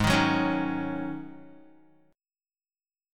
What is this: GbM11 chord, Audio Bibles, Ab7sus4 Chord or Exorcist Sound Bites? Ab7sus4 Chord